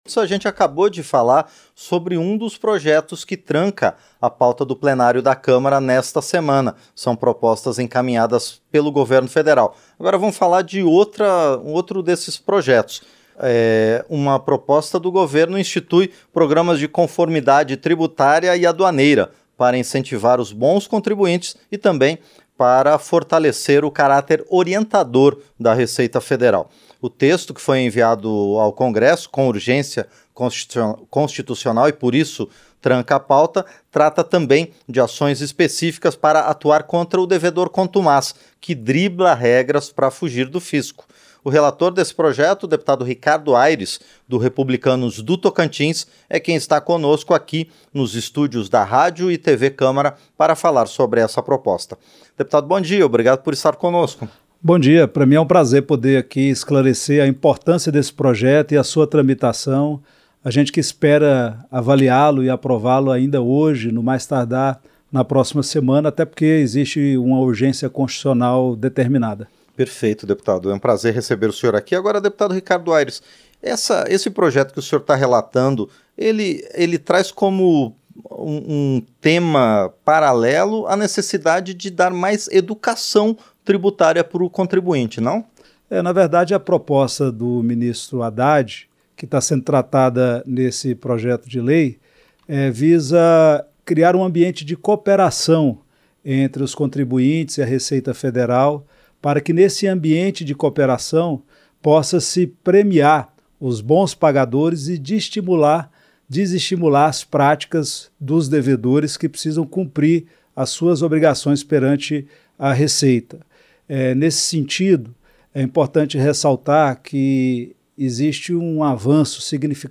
Entrevista - Dep. Ricardo Ayres (Republicanos-TO)